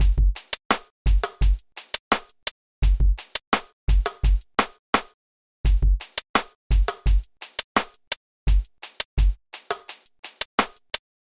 无梦之鼓
描述：与弦乐和钢琴循环配合得很好
Tag: 85 bpm Acoustic Loops Drum Loops 1.91 MB wav Key : Unknown